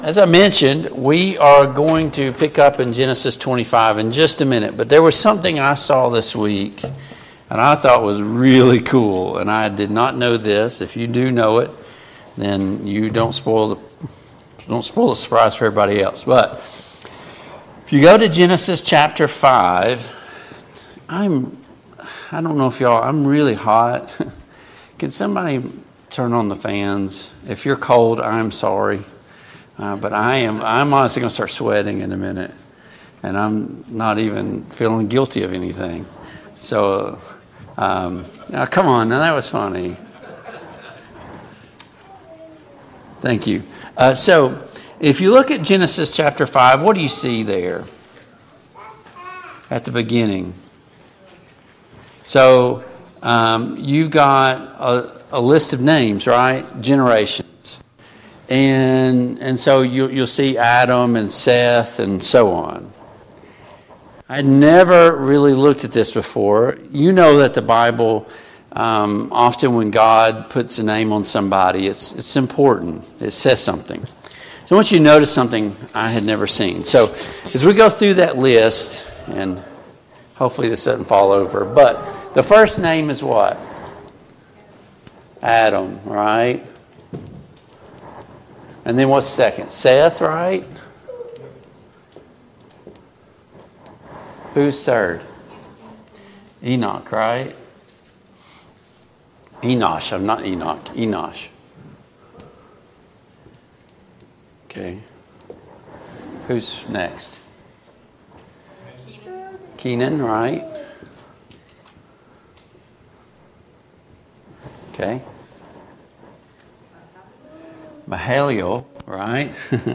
Genesis 26 Service Type: Family Bible Hour « What is the church’s responsibility to wayward members? 9.